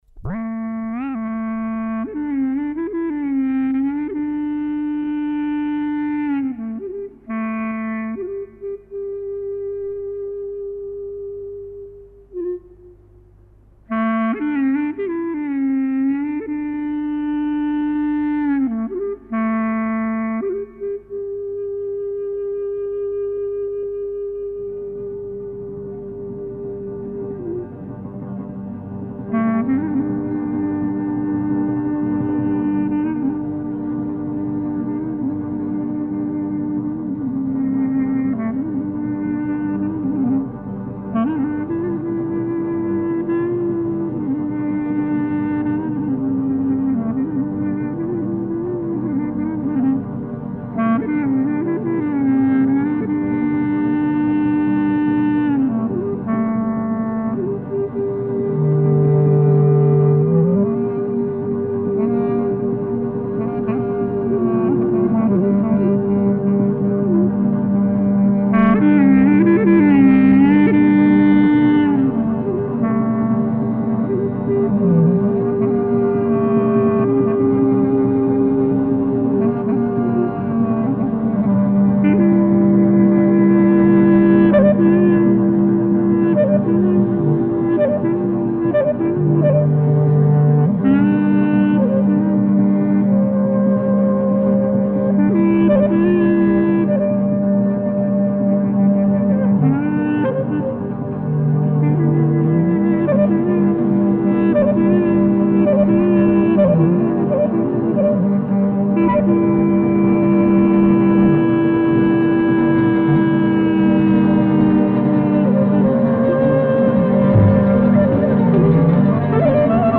Kjo kaba konsiderohet si një nga kabatë toske njëpjesëshe, më virtuoze me saze, një reflektim me shumë vlerë i fillesave të kabave të këtij lloji, të krijuara dhe të realizuara nga mjeshtrit e mëdhenj, pararendës të Sazeve të Leskovikut, Përmetit e të Korҫës.
Në qendër të saj është një motiv vajtimor i prezantuar nga gërneta solo, njësoj si të ishte një vajtojcë. Më pas kabaja, “e qara”, zhvillohet nën ison e përmbajtur të sazeve dhe prerjes së gërnetës së dytë. Përsëritja e vazhdueshme e motivit sjell zhvillimin e tij në regjistrin e mesit të gërnetës duke rritur intensitetin emocional dhe duke krijuar një isopolifoni në grup, e cila pasurohet edhe me ndërfutjen e fyellit. Kulminacioni arrihet në regjistrin më të lartë të gërnetës, ndërkohë që linjat solistike melodike janë të gjitha të ndërthurura në “fraktus” të plotë polifonik.